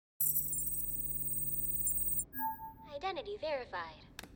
Squid Game Elevator Id Verified Sound Button - Free Download & Play